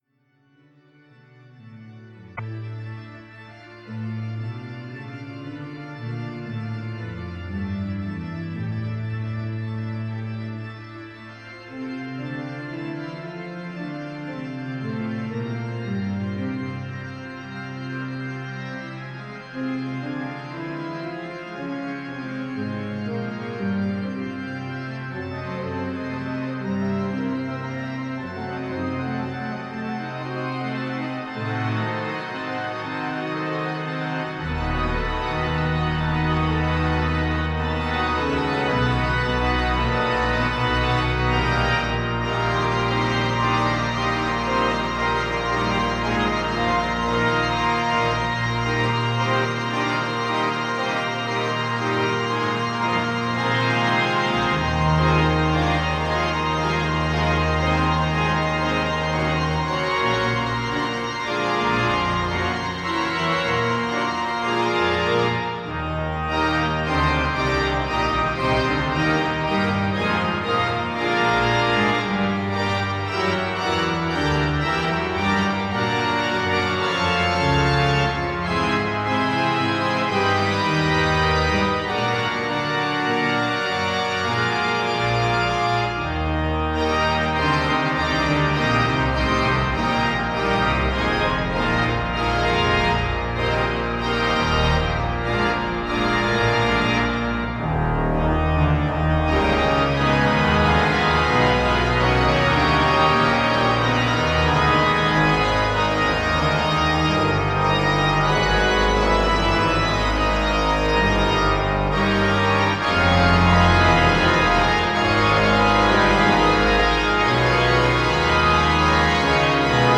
Sound Extracts From the Rieger Organ
The Rieger Organ of Christchurch Town Hall, New Zealand
Recorded in Christchurch Town Hall 29-31 January 2010.